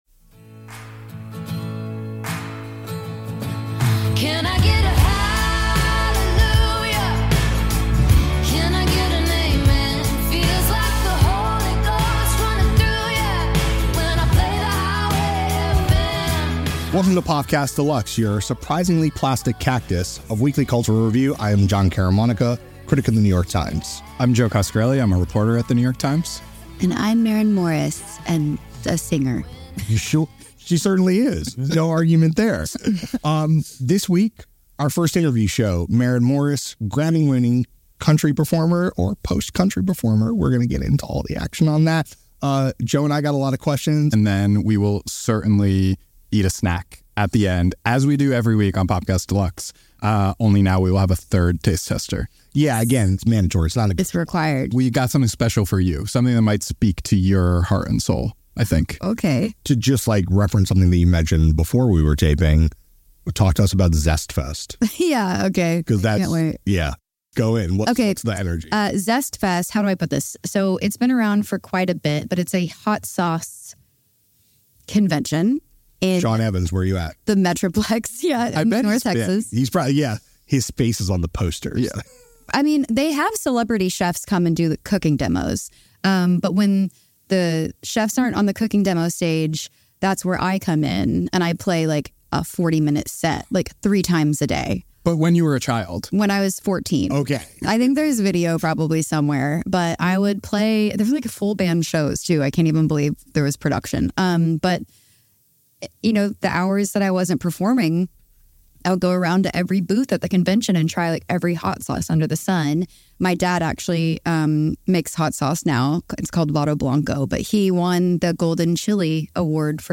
Maren Morris: The (Deluxe) Interview